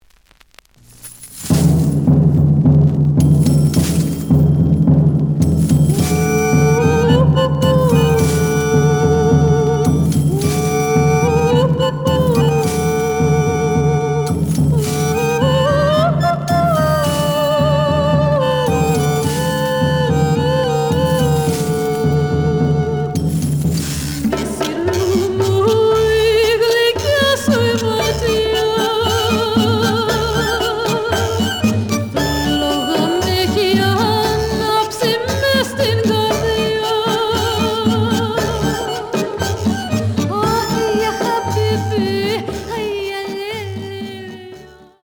The audio sample is recorded from the actual item.
●Genre: Folk / Country